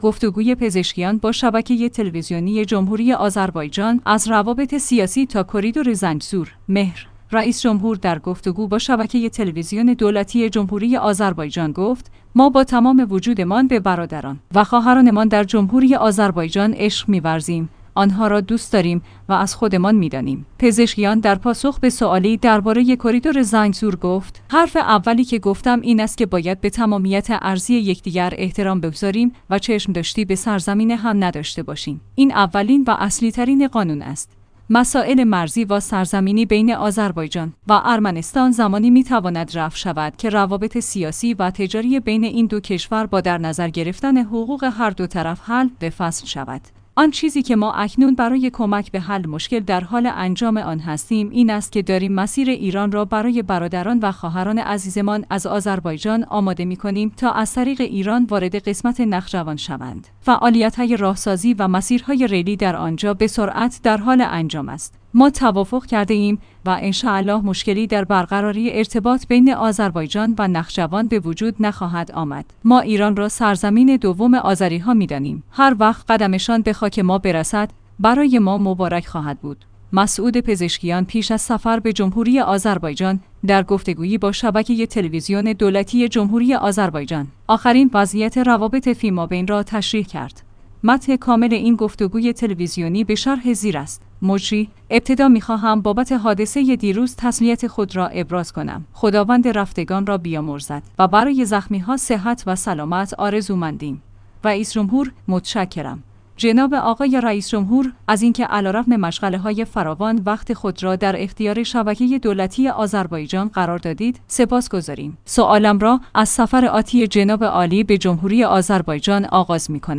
گفتگوی پزشکیان با شبکه تلویزیونی جمهوری آذربایجان؛ از روابط سیاسی تا کریدور زنگزور